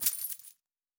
Coins 02.wav